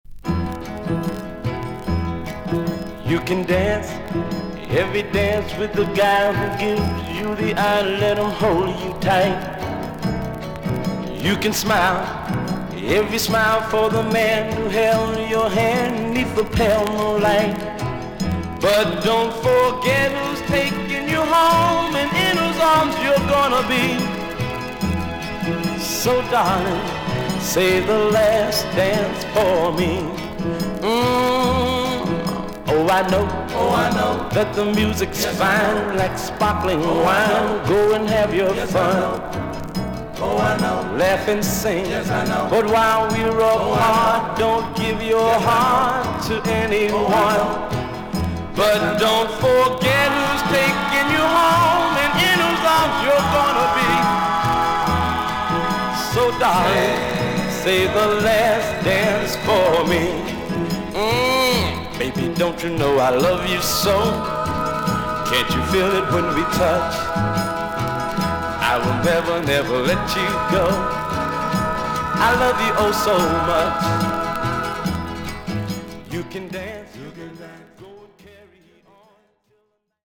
少々軽いパチノイズの箇所あり。少々サーフィス・ノイズあり。クリアな音です。
R&Bコーラス・グループ。